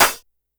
Snares
snr_03.wav